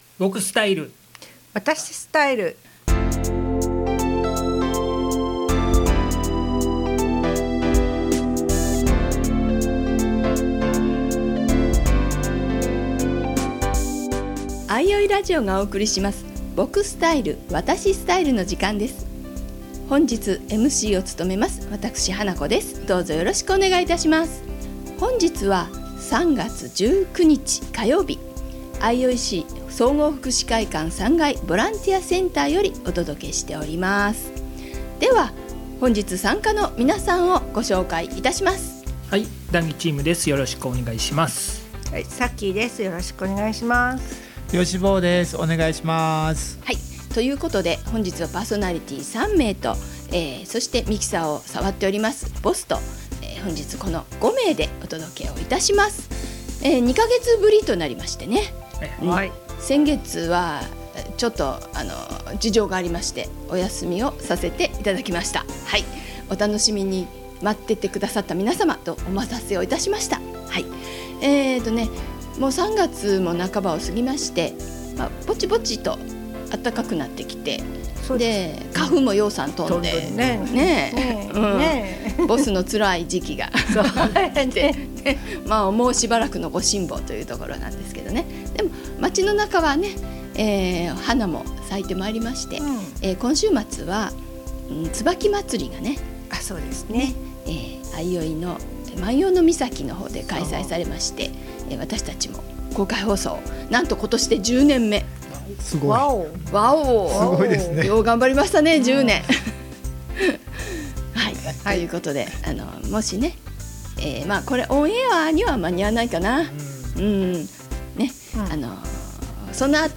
この番組はマイノリティーの声なき声をお届けすることで、心のバリアフリー化を目指し「多様性を認め合う社会を作ろう」という相生市発のマイノリティーラジオです。